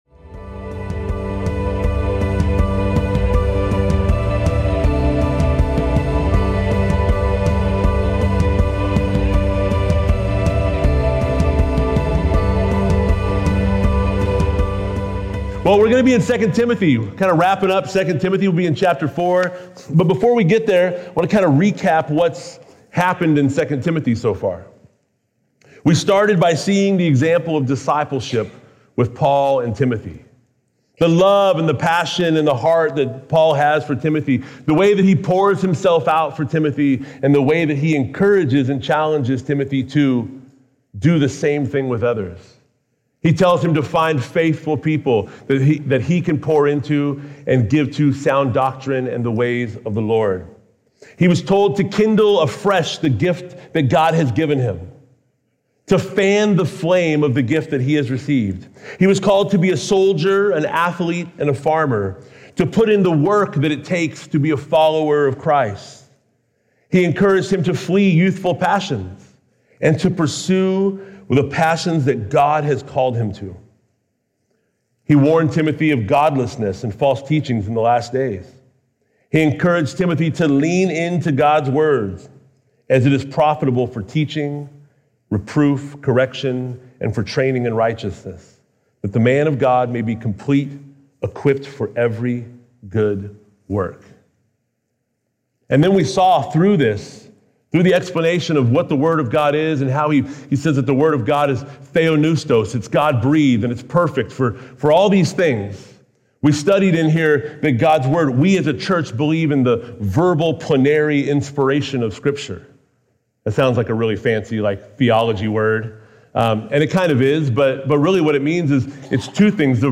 This is an audio podcast produced by Calvary Chapel Eastside in Bellevue, WA, featuring live recordings of weekly worship services.